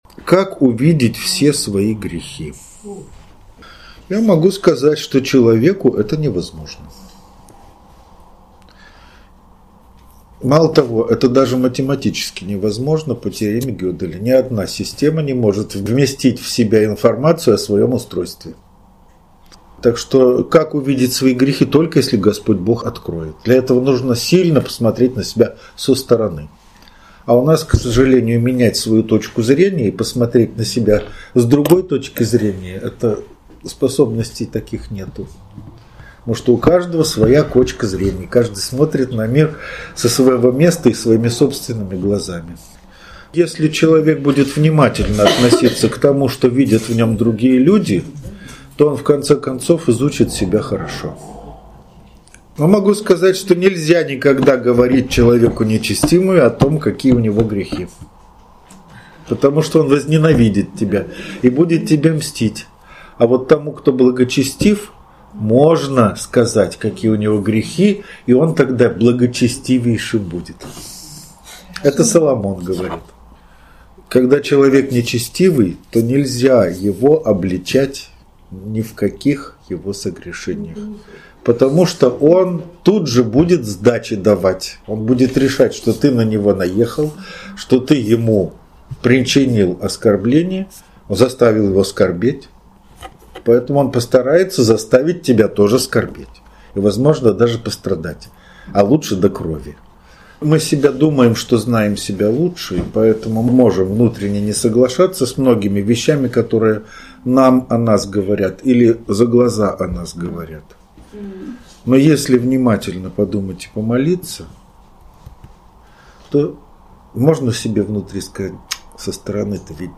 Из лекции 9 ноября 2020 года. Псалом 118:97-120 и ответы на вопросы: как увидеть все свои грехи и терпеть Господа?